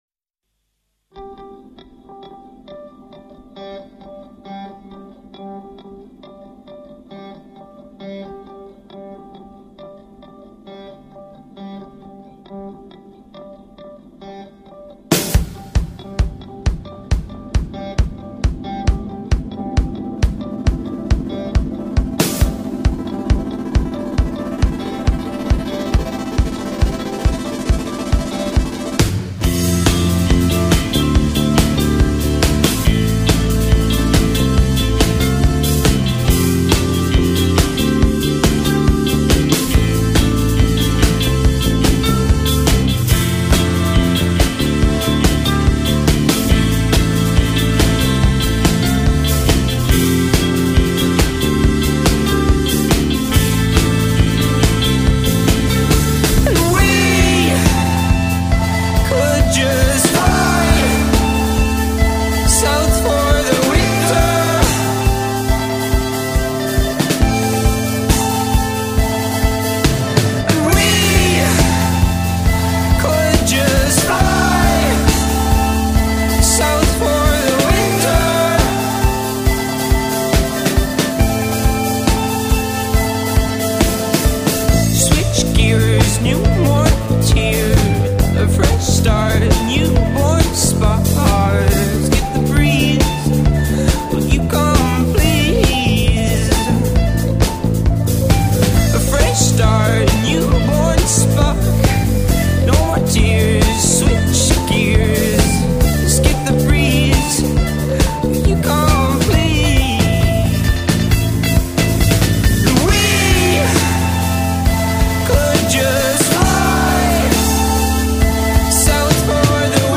indie pop